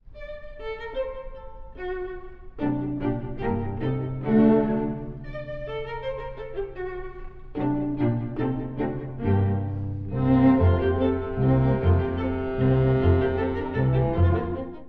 ↑古い録音のため聴きづらいかもしれません！（以下同様）
彼の十八番のスケルツォだが、ちょっと甘くユーモラスな雰囲気です。
しかし、これは少し大人びた感じ…